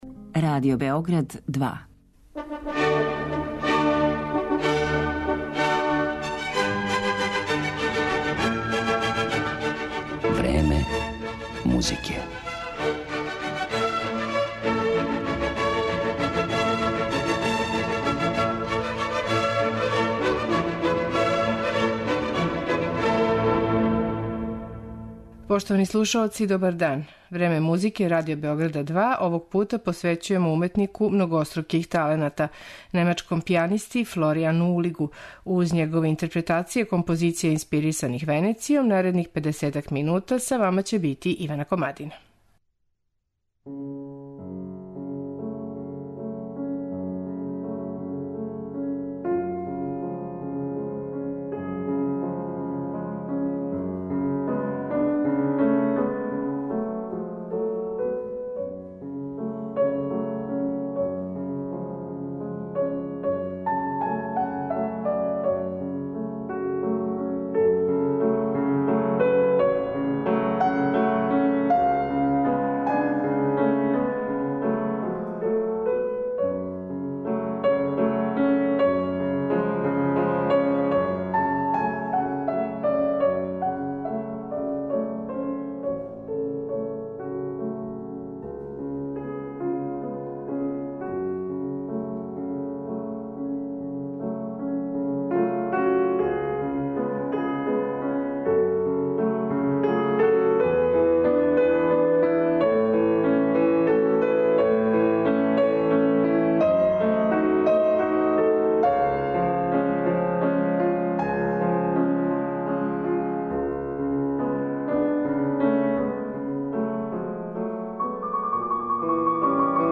овај млади немачки пијаниста